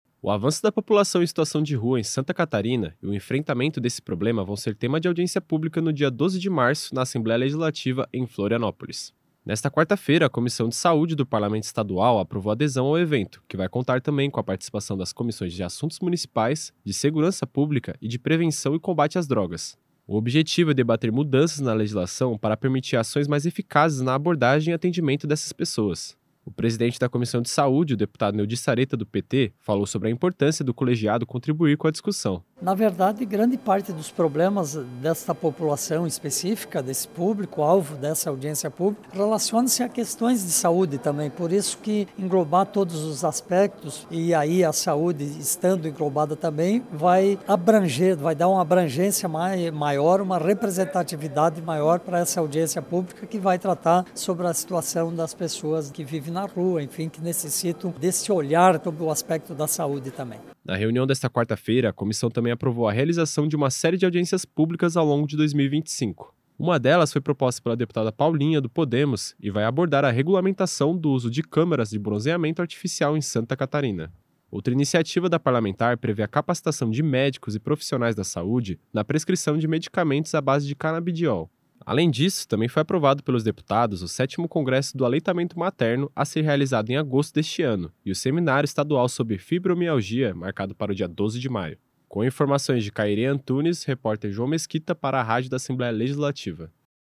Entrevista com:
- deputado Neodi Saretta (PT), presidente da Comissão de Saúde da Assembleia Legislativa.